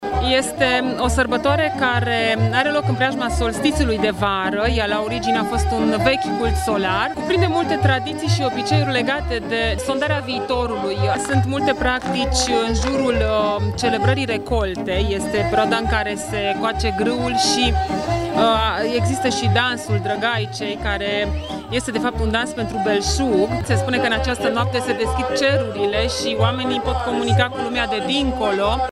Zeci de reghineni au participat duminică seara în curtea Muzeului Etnografic din Reghin la un eveniment dedicat Sânzienelor, sărbătoare tradițională românească, care face referire la zânele câmpiilor și pădurilor, făpturi mitice care în noaptea de 23 iunie împart belșug, sănătate și iubire oamenilor.